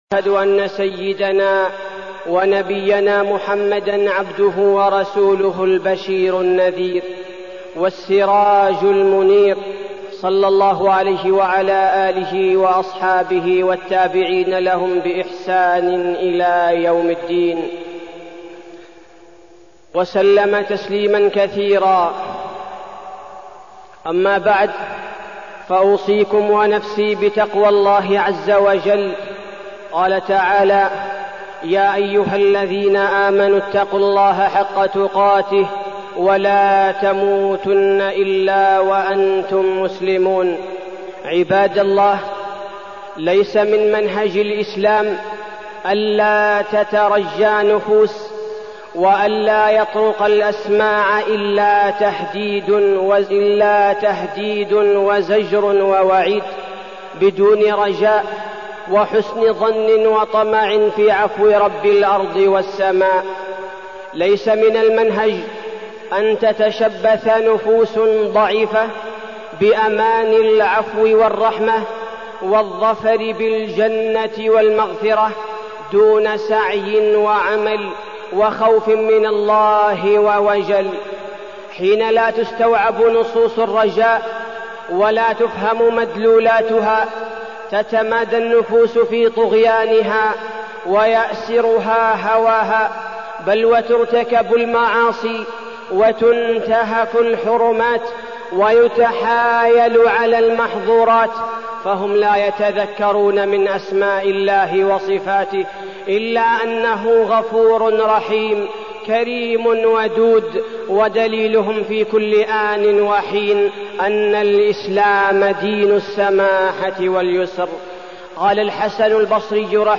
تاريخ النشر ٢٢ ربيع الثاني ١٤١٩ هـ المكان: المسجد النبوي الشيخ: فضيلة الشيخ عبدالباري الثبيتي فضيلة الشيخ عبدالباري الثبيتي الخوف والرجاء